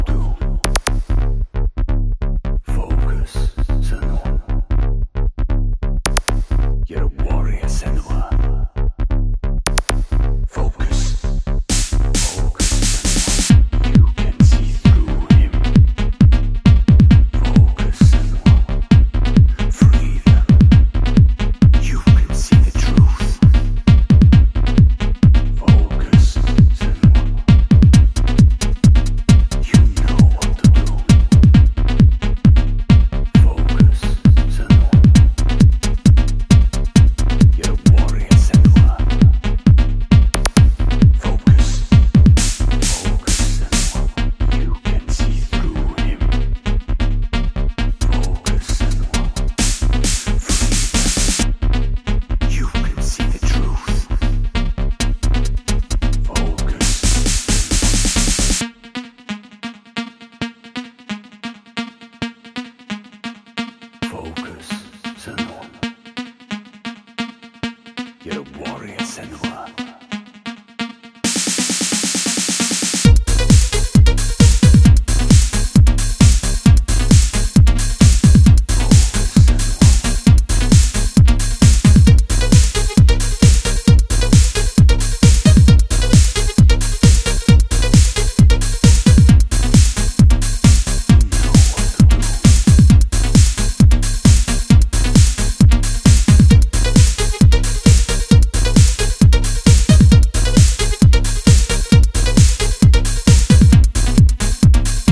A journey into the most futuristic and enveloping electro